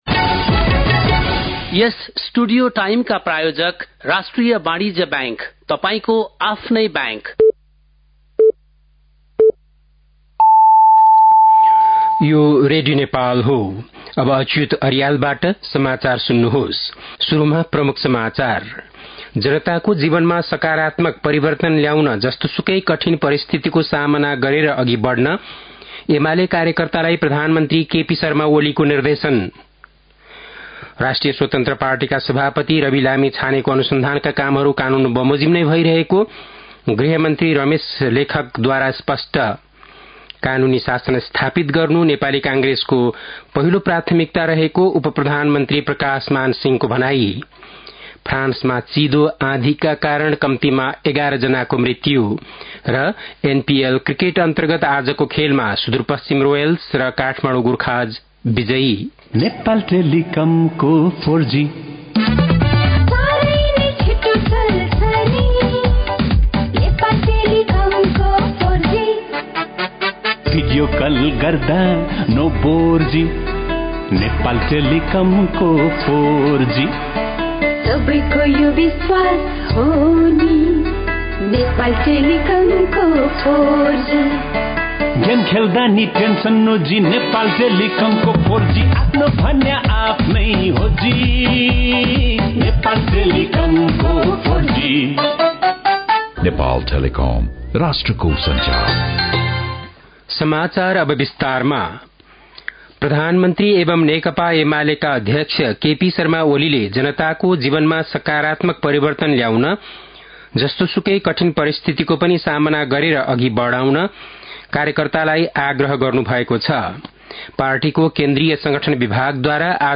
बेलुकी ७ बजेको नेपाली समाचार : १ पुष , २०८१
7-PM-Nepali-News-8-30.mp3